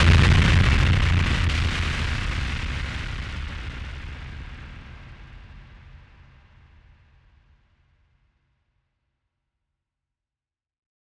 BF_DrumBombA-09.wav